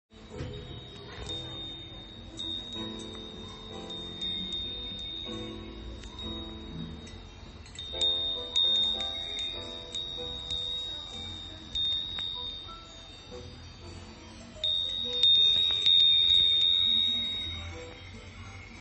南部鉄風鈴釣灯籠 小（数量割引有り）
サイズ 灯籠 高さ約8cm 箱入り 響きが違う南部風鈴 短冊のデザインは変わることがあります。